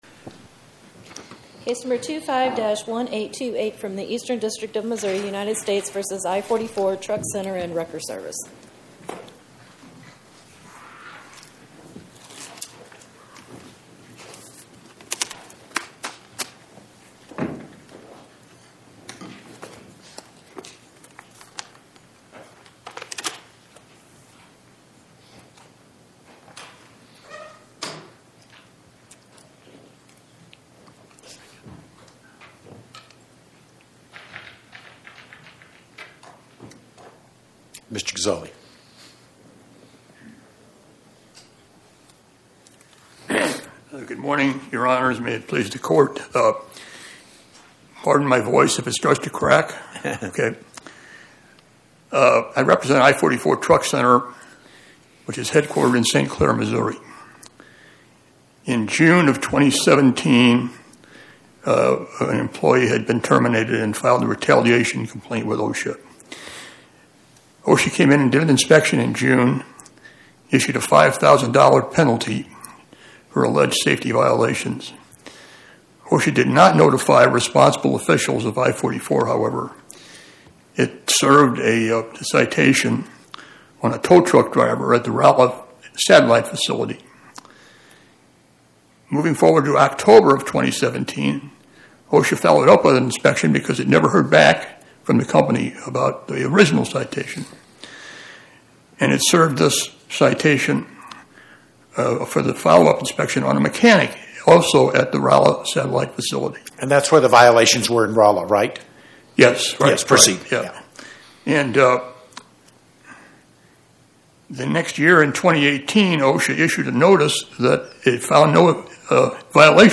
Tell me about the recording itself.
My Sentiment & Notes 25-1828: United States vs I-44 Truck Cntr & Wrecker Svc Podcast: Oral Arguments from the Eighth Circuit U.S. Court of Appeals Published On: Thu Jan 15 2026 Description: Oral argument argued before the Eighth Circuit U.S. Court of Appeals on or about 01/15/2026